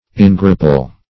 Engrapple \En*grap"ple\